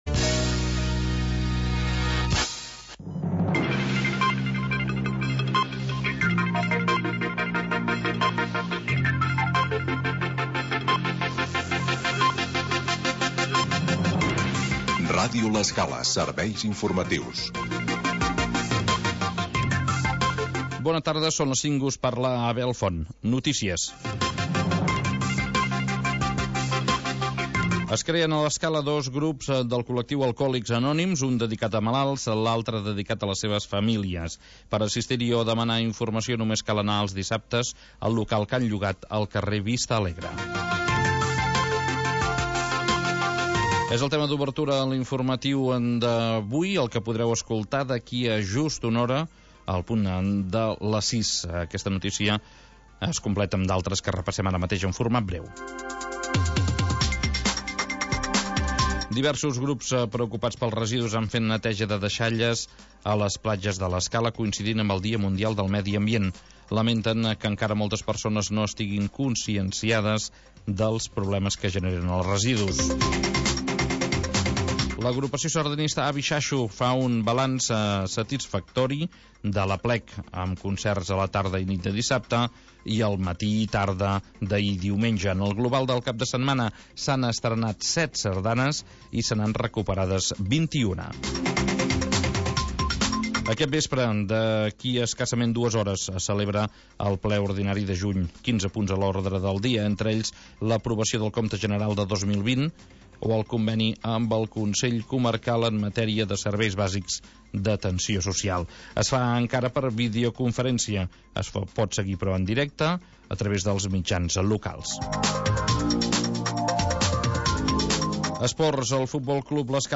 Magazín territorial que parla del mar